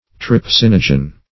Trypsinogen \Tryp*sin"o*gen\, n. [Trypsin + -gen.] (Physiol.)